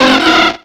Cri de Goélise dans Pokémon X et Y.